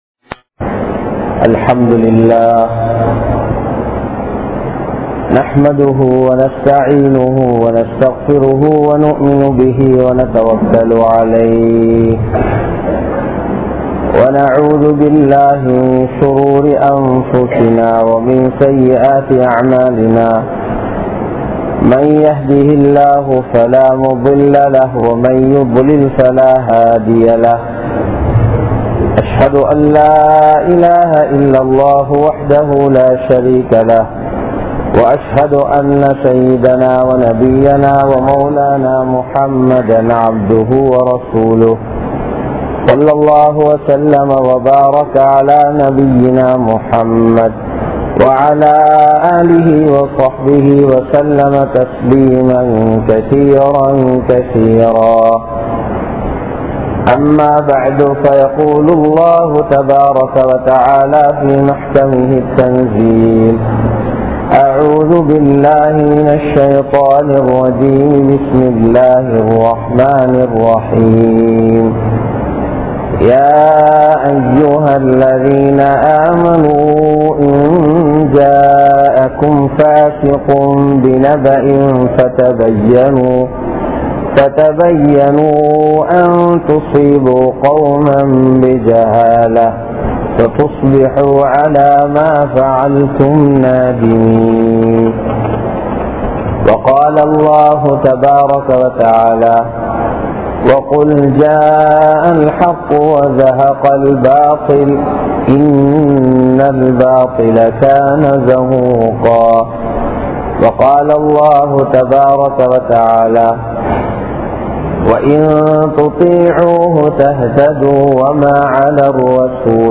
Manaqibus Sahaba | Audio Bayans | All Ceylon Muslim Youth Community | Addalaichenai